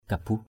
/ka-buh/ maong: ‘pabuh’ F%b~H
kabuh.mp3